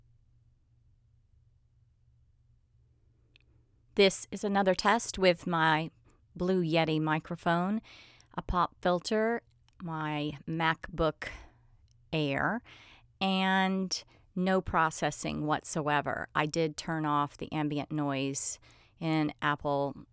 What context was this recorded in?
Okay, another test now that the dumb ambient noise filter is turned off! Yay! The milk jug quality sound went away, but the reduction was doing something useful.